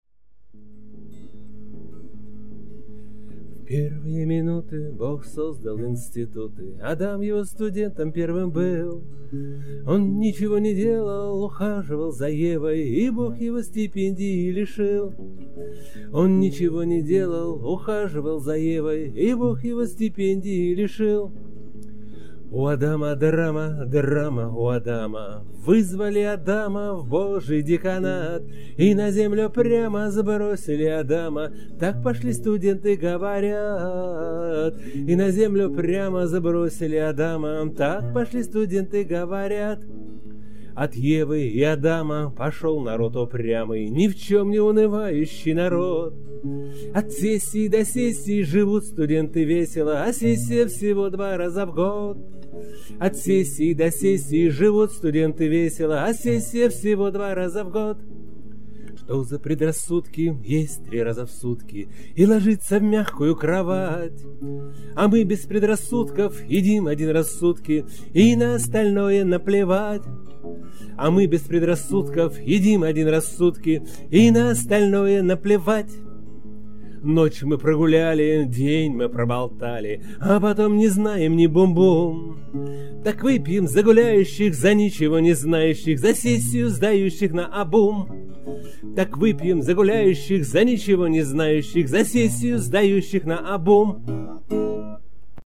Играется боем без приглушки струн.